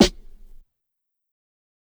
Snares
SNARE_RESTORATION.wav